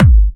VEC3 Bassdrums Trance 71.wav